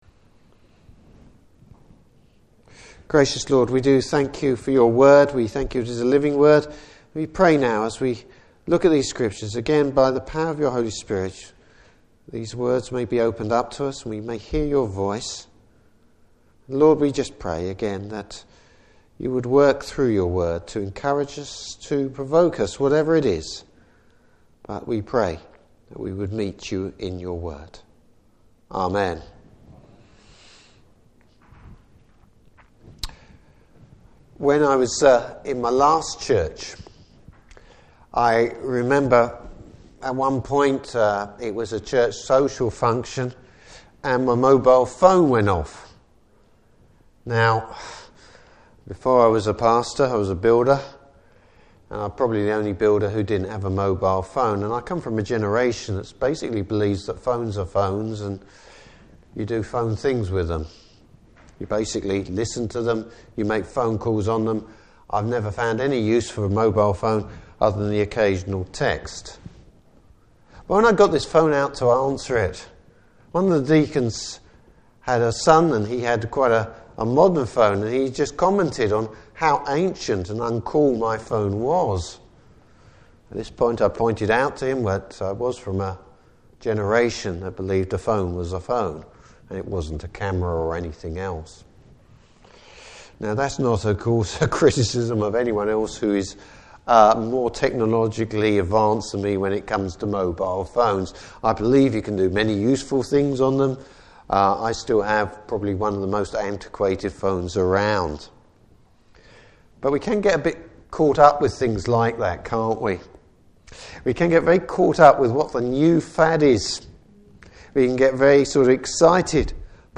Service Type: Evening Service Bible Text: 2 Corinthians 12:1-10.